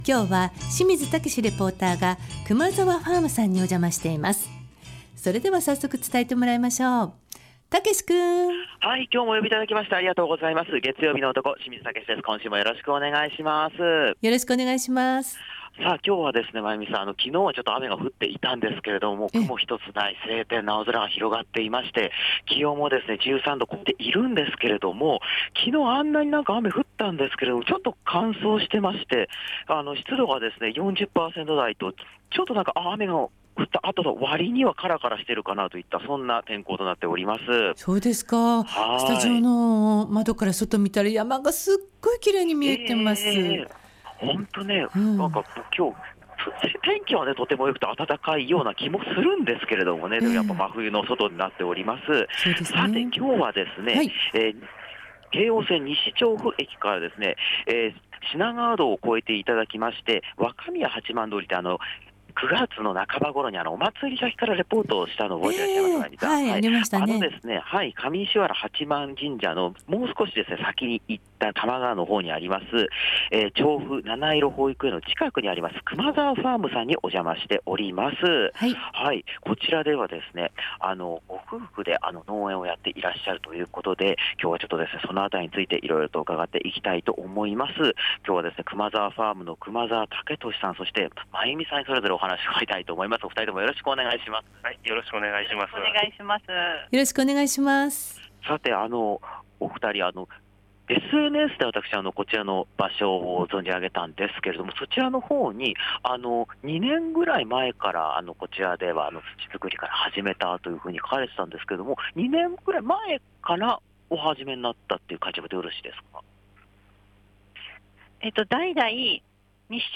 澄み切った青空の下からお届けした、本日の街角レポートは 多摩川1丁目にある農園『くまざわファーム』さんからのレポートでした。